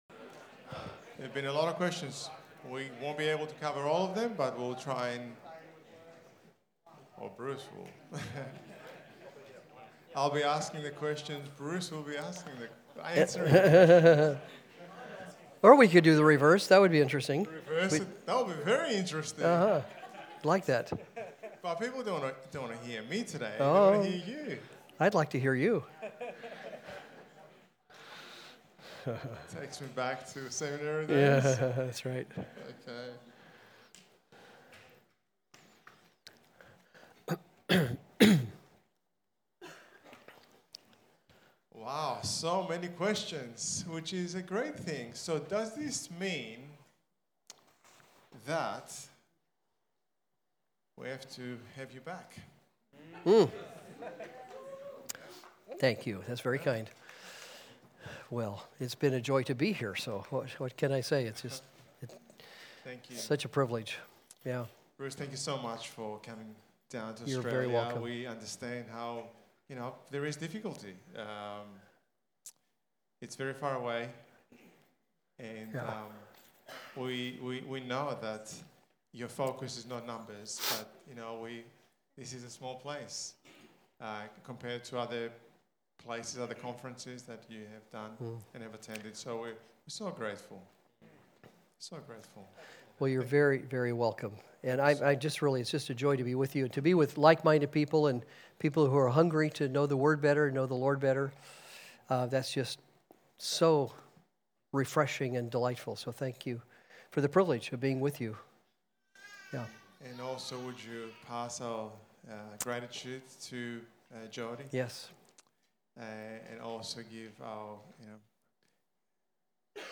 #10 – God Is Conference Q&A Session